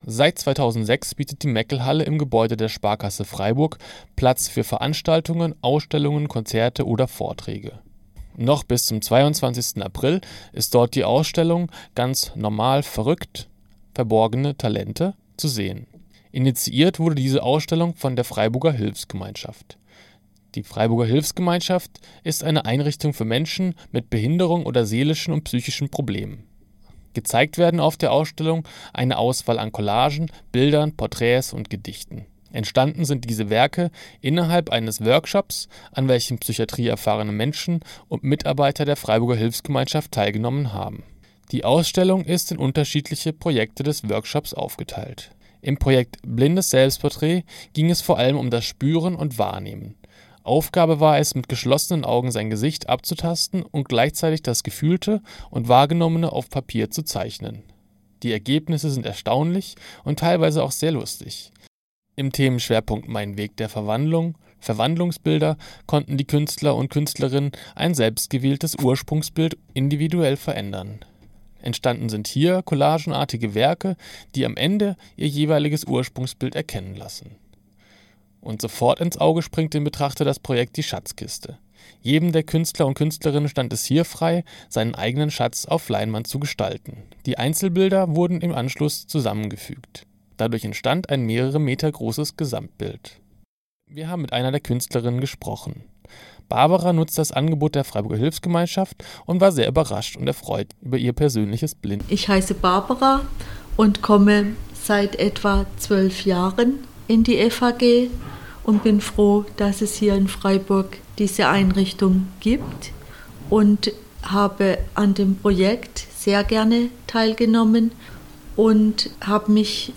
Bericht über die Inhalte der Ausstellung und Gespräch